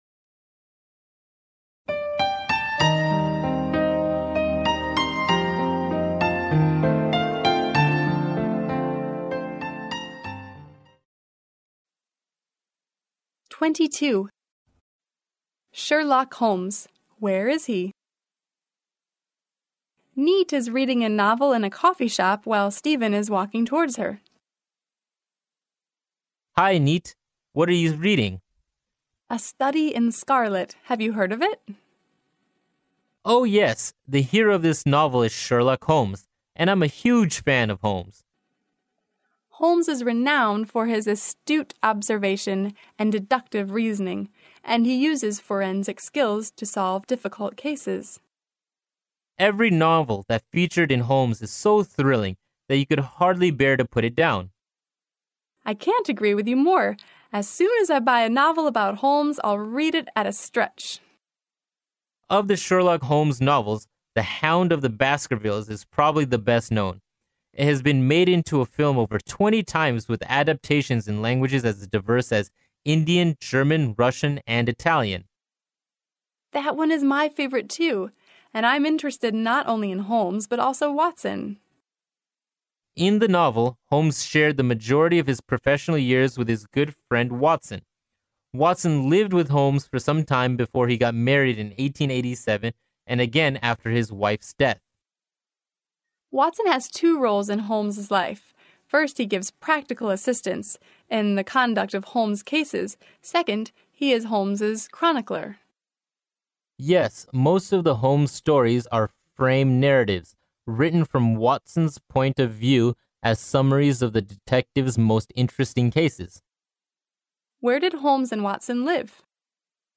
剑桥大学校园英语情景对话22：寻找福尔摩斯（mp3+中英）